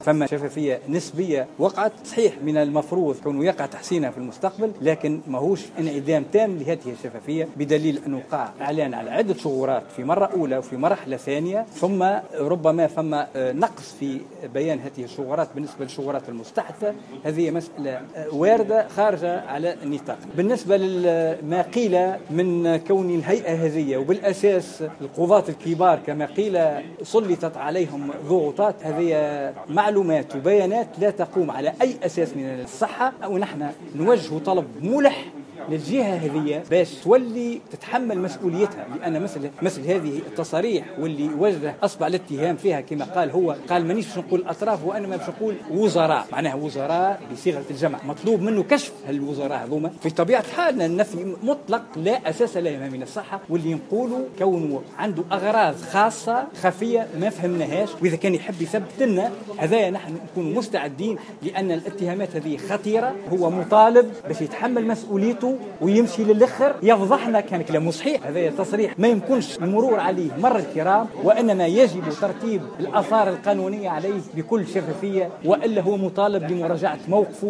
واعتبر العياري في تصريح لـ"جوهرة أف أم" على هامش ندوة صحفية عقدها، أنّ تصريحات المرصد خطيرة وتقتضي توضيحات، نافيا وجود ضغوطات على الهيئة.